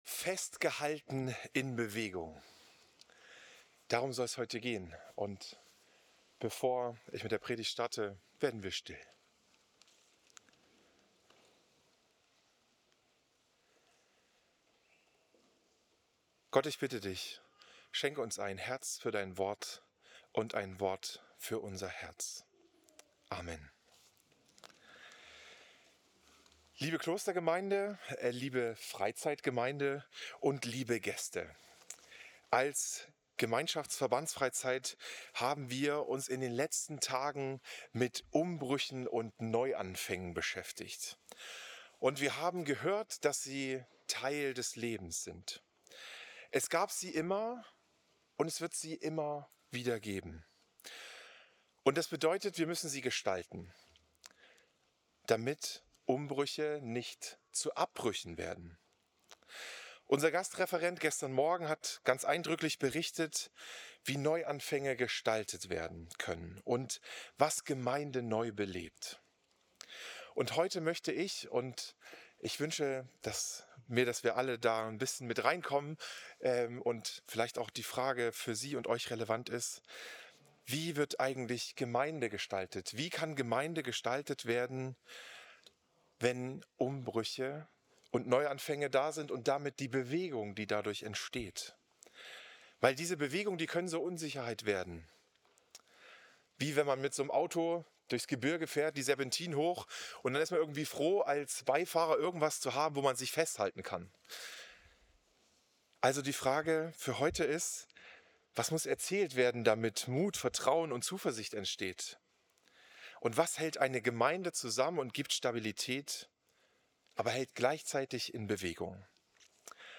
Predigt
Christus-Pavillon Volkenroda, 5. Oktober 2025